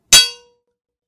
anvil-hit.BCI9RamI.mp3